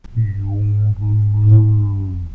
:P) February, 12th 2012 As per popular request I hereby also add (as an attachment) the proper pronunciation soundfile of this bot's true name (c'mon this was is way easy).&